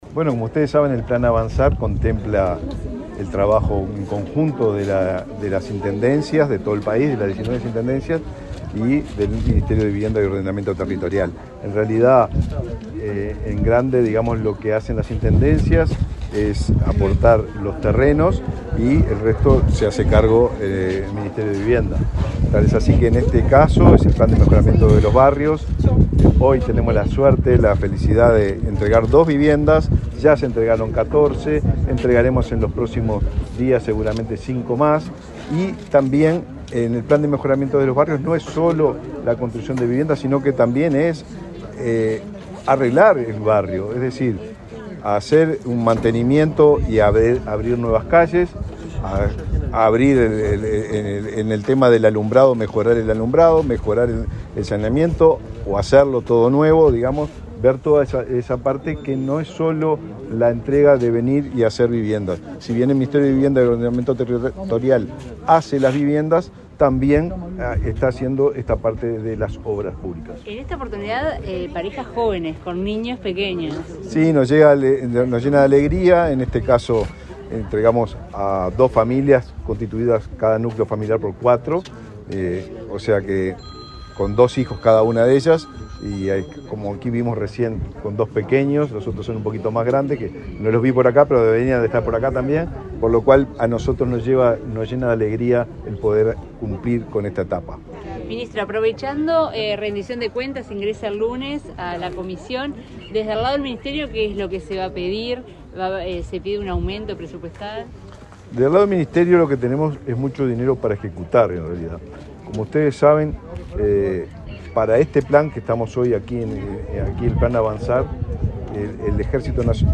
Declaraciones del ministro de Vivienda, Raúl Lozano
El ministro de Vivienda, Raúl Lozano, dialogó con la prensa luego de participar en el acto de entrega de viviendas en el barrio Nueva Esperanza, de